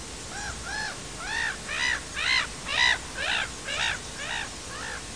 Эти пернатые очень шумные. Издают трескучие крики, которые имеют квохтаньем. В научной среде его характеризуют как «смех чаек».
ozernaya-ili-rechnaya-chajka-larus-ridibundus.mp3